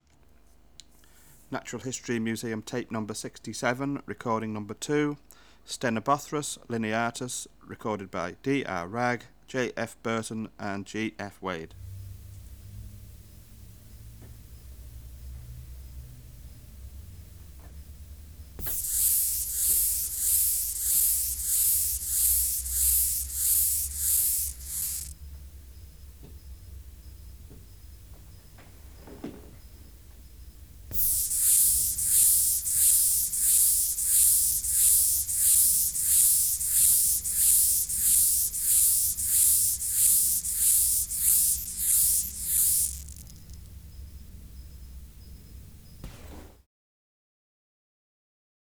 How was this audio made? Extraneous Noise: Squeaking tape recorder Substrate/Cage: Muslin-covered wooden cage Recorder: Kudelski Nagra III